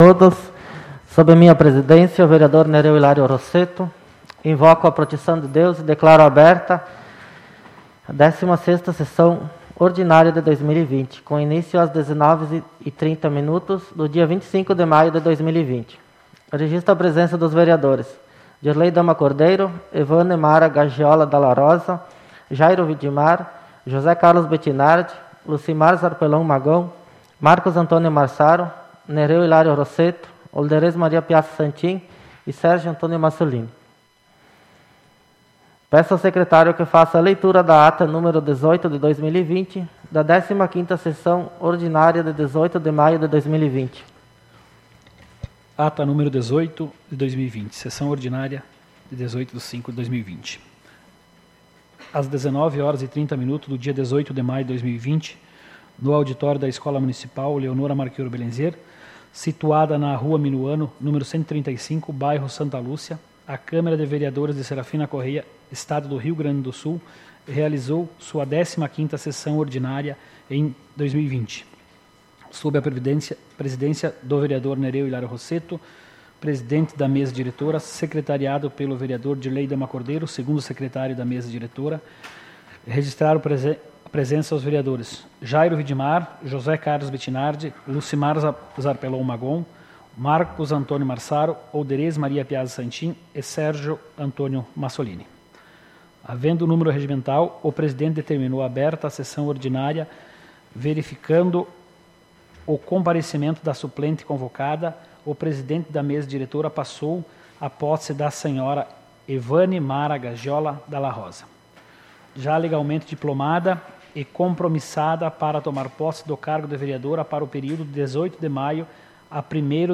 SAPL - Câmara de Vereadores de Serafina Corrêa - RS